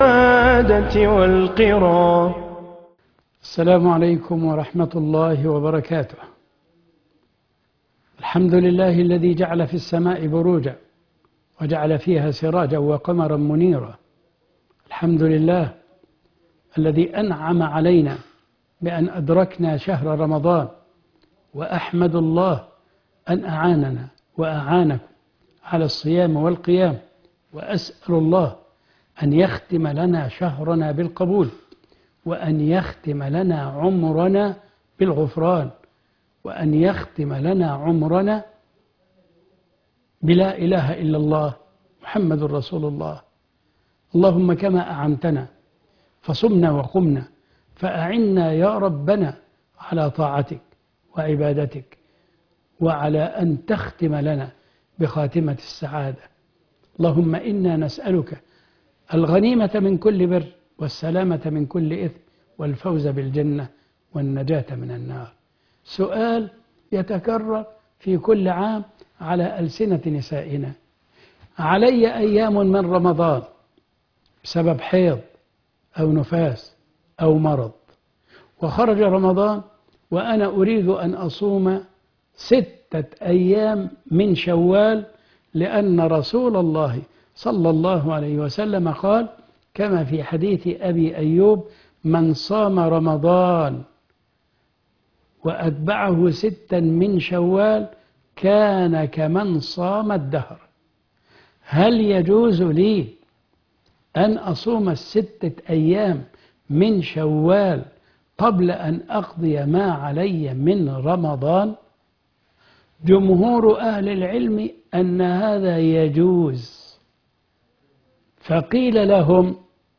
عنوان المادة صيام النافلة قبل القضاء ( 20/4/2023 ) فتاوي النساء